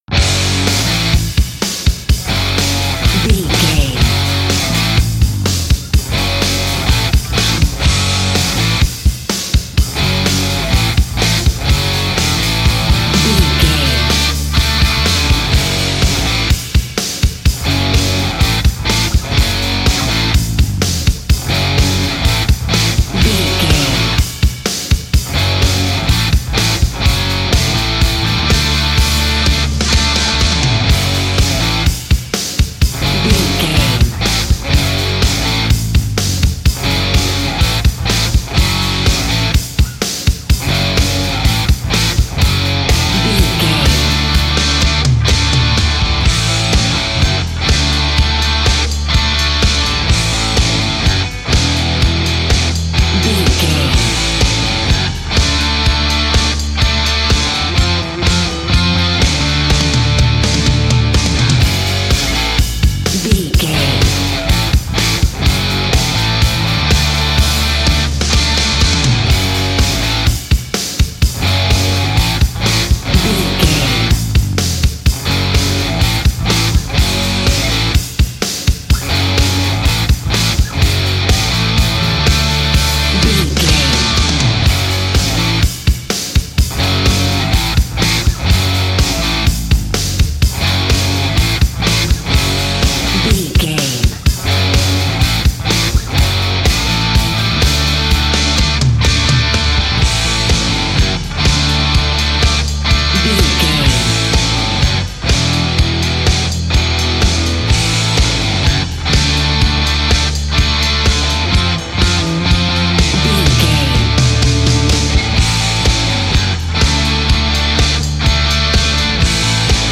Ionian/Major
F#
drums
electric guitar
bass guitar
pop rock
hard rock
aggressive
energetic
nu metal
alternative metal